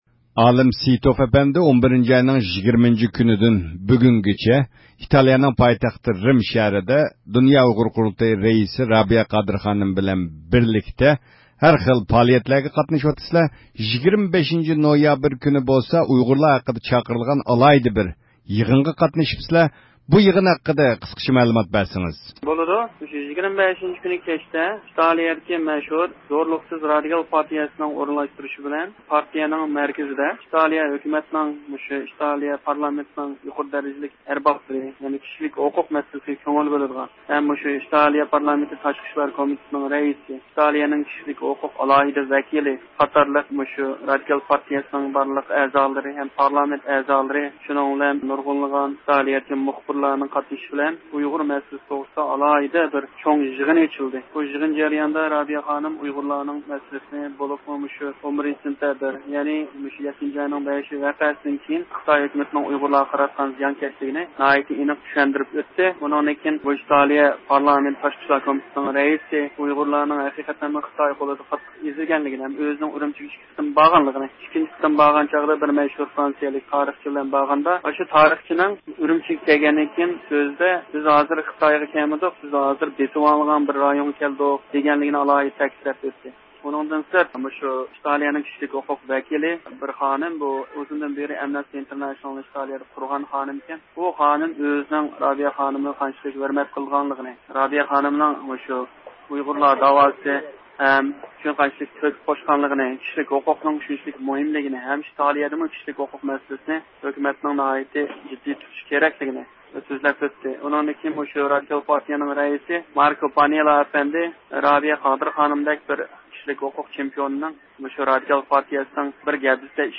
د ئۇ ق رەئىسى، ئۇيغۇر مىللىي ھەرىكىتىنىڭ يولباشچىسى رابىيە قادىر خانىم سۆزىنىڭ ئاخىرىدا پۈتۈن ئۇيغۇرلارنىڭ مۇبارەك قۇربان ھېيتىنى تەبرىكلىدى.